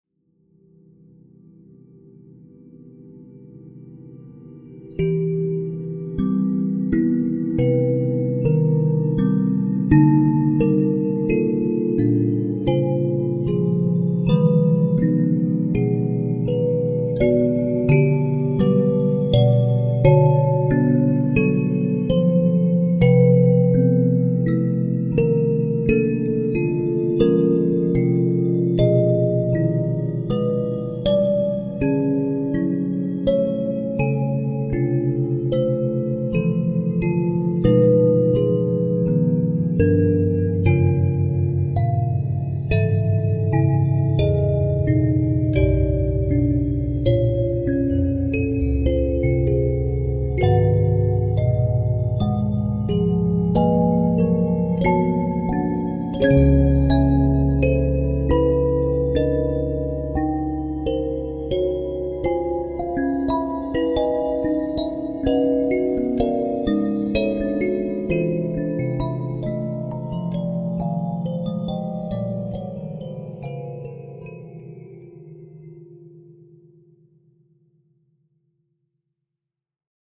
华丽的拇指钢琴
• 优美独特的传统非洲民乐器，细致入微的细节采集
ARRAY MRIBA Audio demos